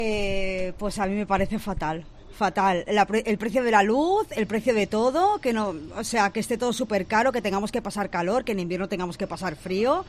Trabajadora de comercio en Barcelona nos da su opinión